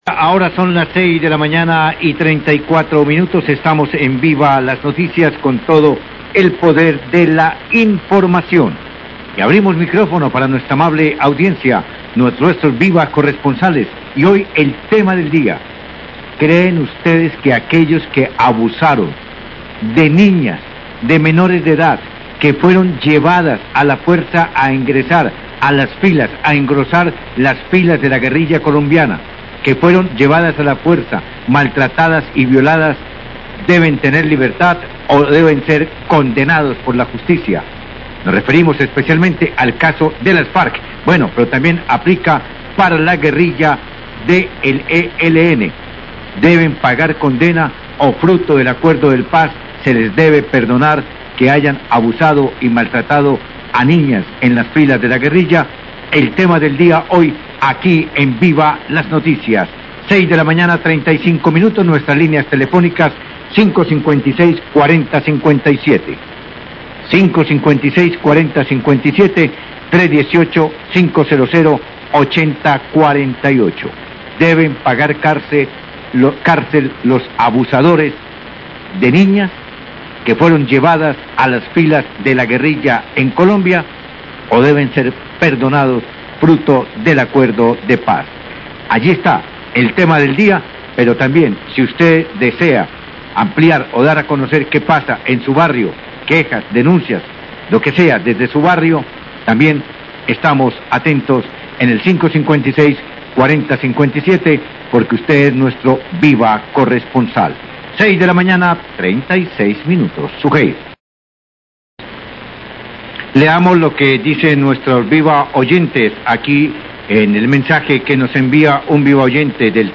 Radio
Abren líneas telefónicas para que los oyentes opinen.